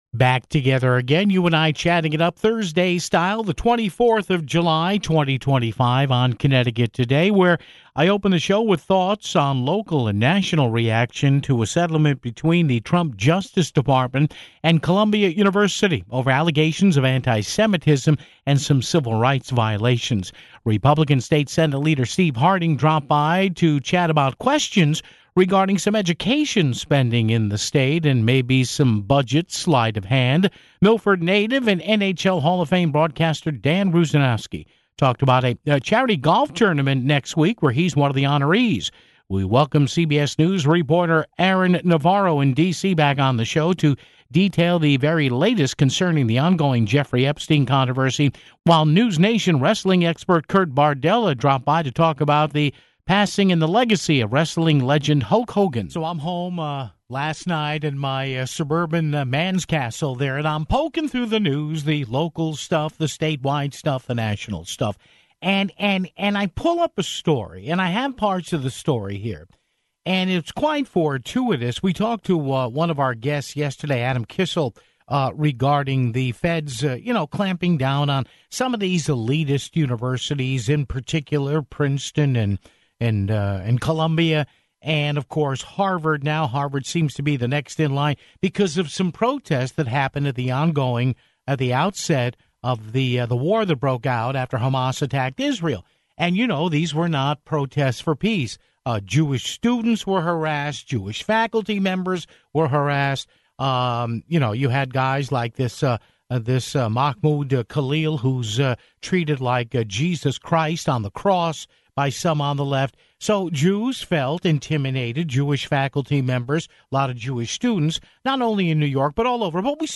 GOP State Senate leader Steve Harding dropped by to chat about questions regarding some education spending in the state (17:34).